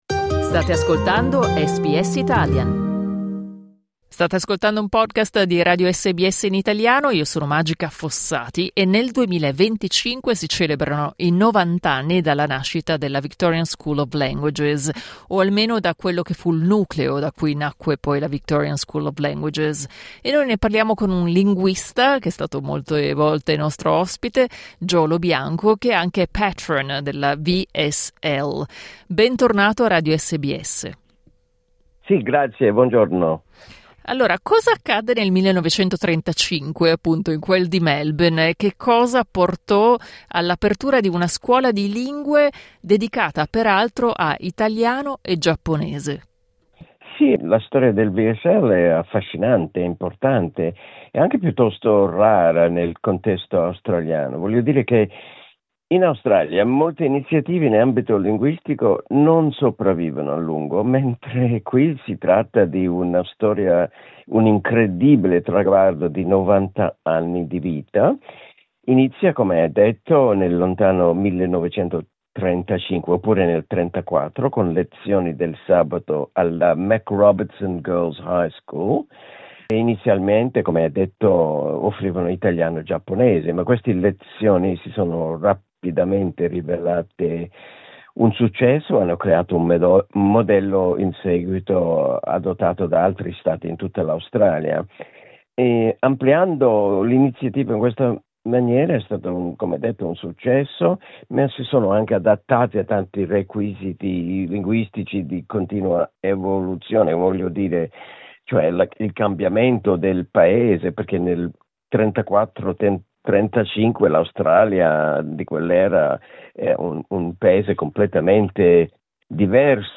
Clicca sul tasto "play" in alto per ascoltare tutta l'intervista Un'immagine della MacRobertson Girls High School, dove si tenevano le prime classi di lingua del sabato.